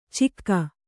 ♪ cikka